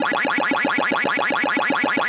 pacghostblue.wav